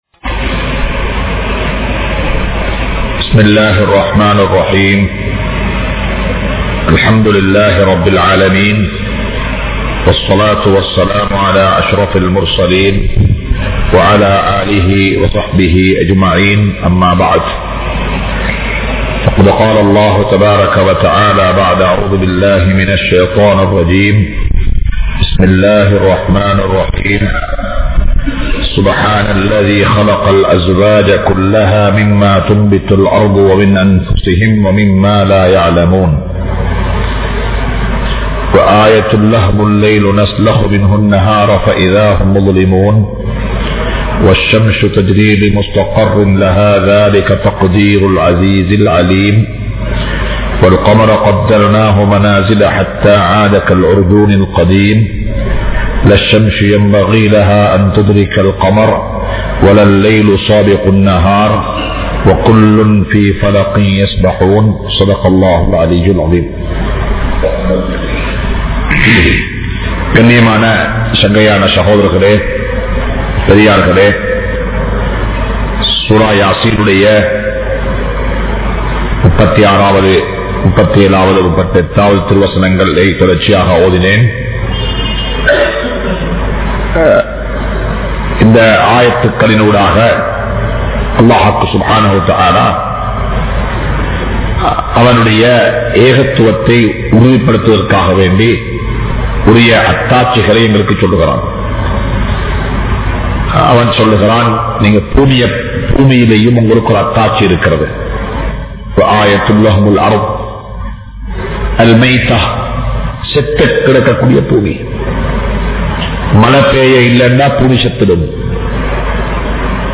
Allah`vukku Nantri Sealuthungal (அல்லாஹ்வுக்கு நன்றி செலுத்துங்கள்) | Audio Bayans | All Ceylon Muslim Youth Community | Addalaichenai
Majma Ul Khairah Jumua Masjith (Nimal Road)